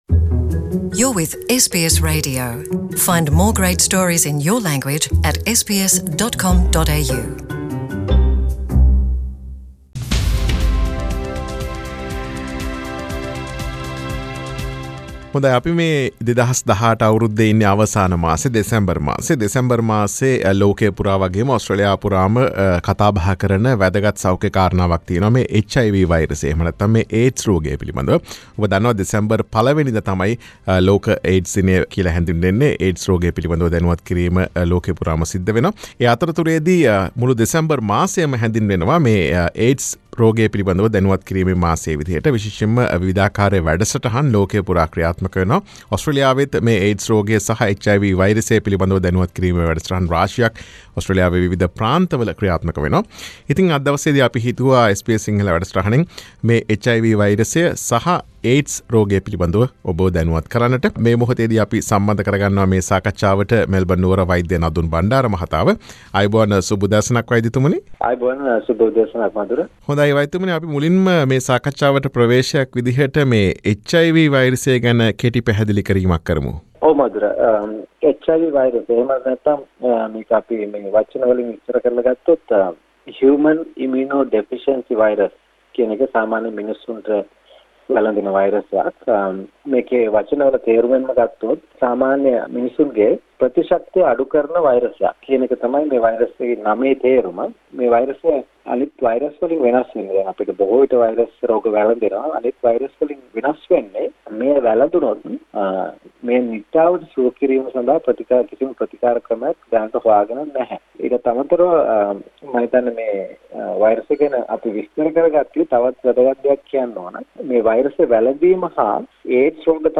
සිදුකළ සාකච්ඡාව.